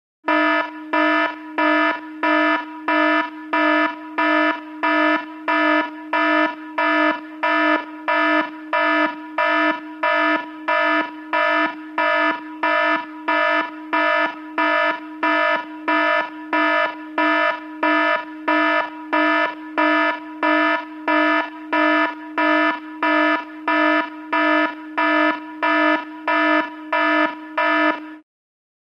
Звуки сигналов тревоги
Корабельная тревожная сирена